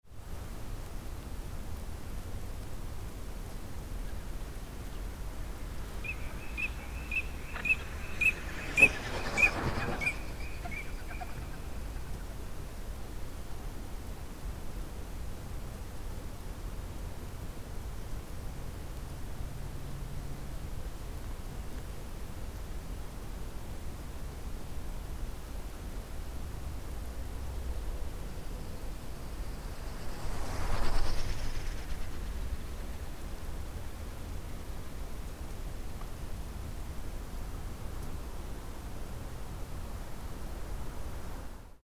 PFR07583, 130331, Eurasian Teal Anas crecca, calls in flight, wing sound,
northwestern Saxony, ORTF, Neumann KM184